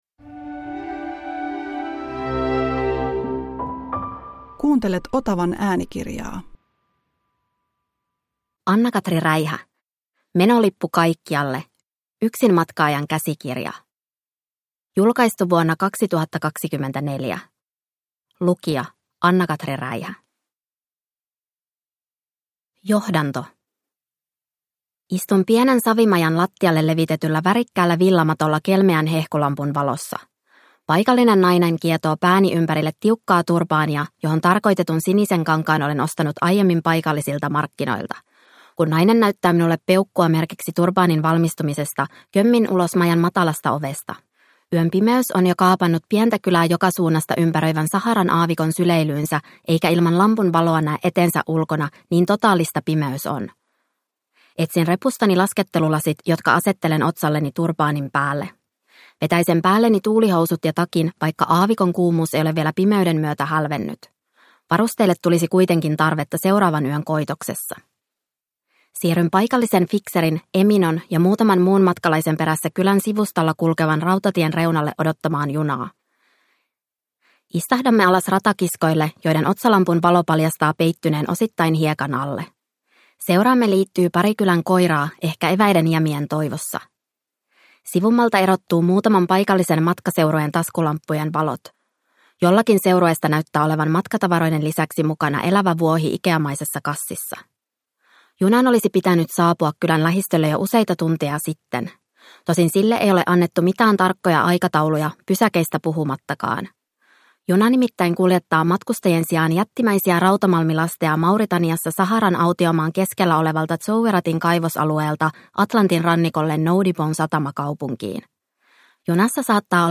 Menolippu kaikkialle – Ljudbok